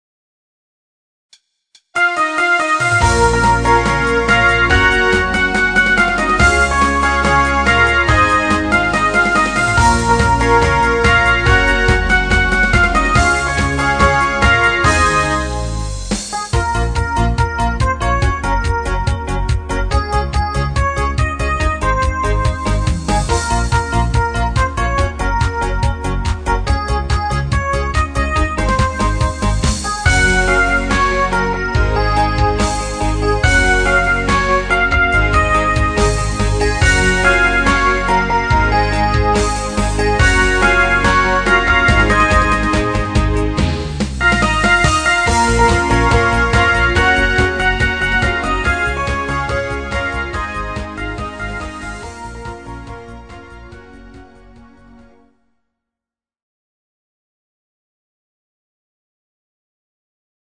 Genre(s): Deutschpop  Partyhits  |  Rhythmus-Style: Discofox